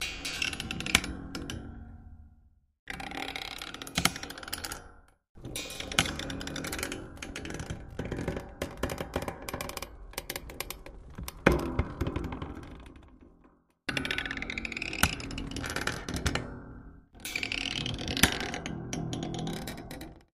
Springs, Bed, Heavy Creak x5